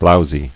[blow·zy]